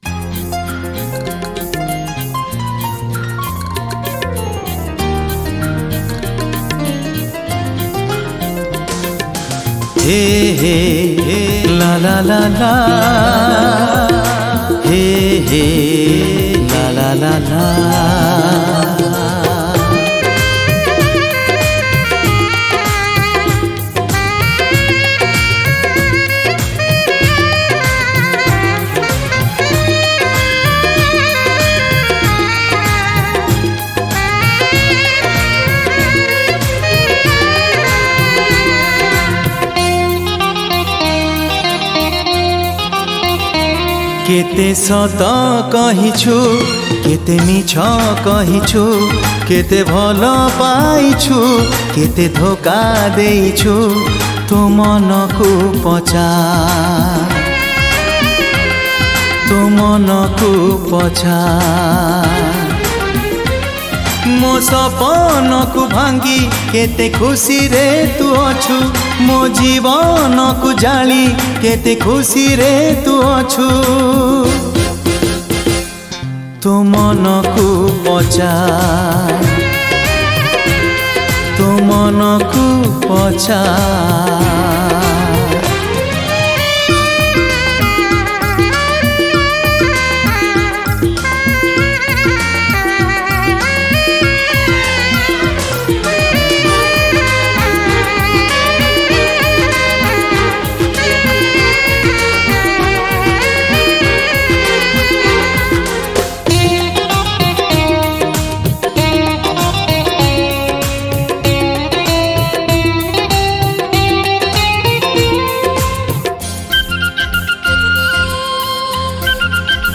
Odia Song Artist